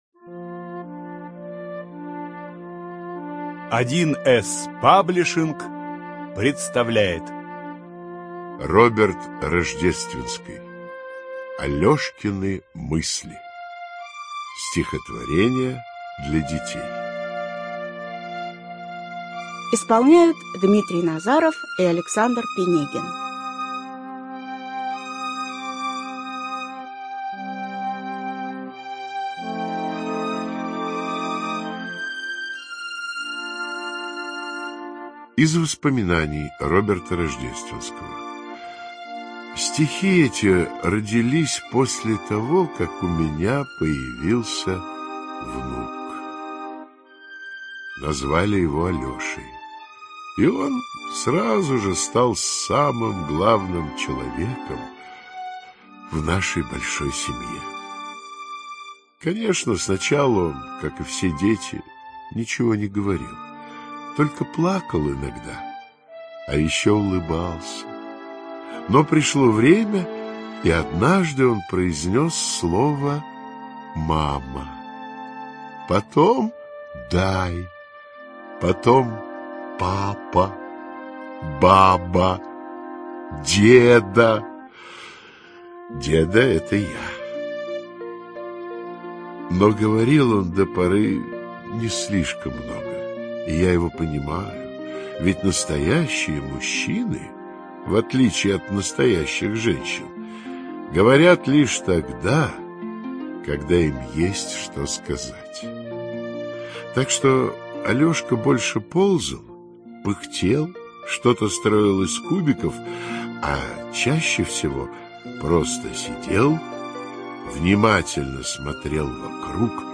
Литературно-музыкальная постановка
Студия звукозаписи1С-Паблишинг